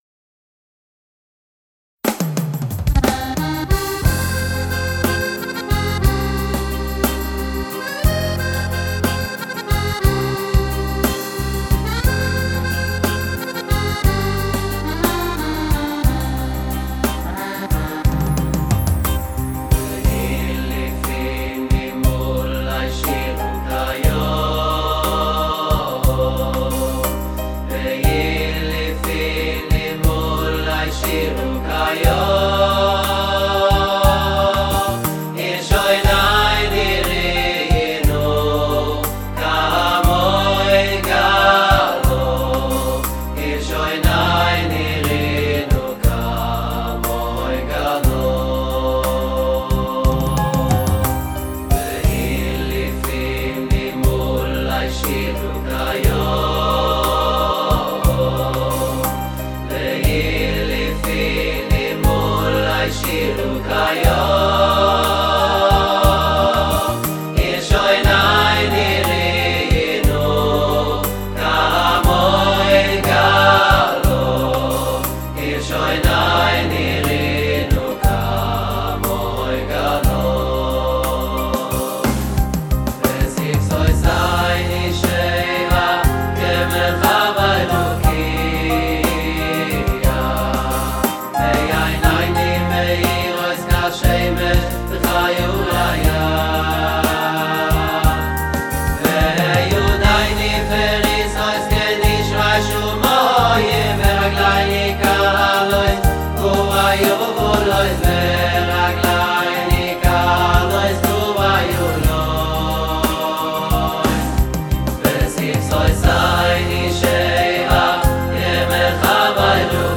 ניגון חדש
לחן וואלס חדש